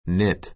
k nit nít ニ ト （ ⦣ kn- で始まる語は k を発音しない） 動詞 三単現 knits níts ニ ツ 過去形・過去分詞 knit knitted nítid ニ テ ド -ing形 knitting nítiŋ ニ ティン ぐ 編む; 編み物をする My mother knitted me a sweater [a sweater for me].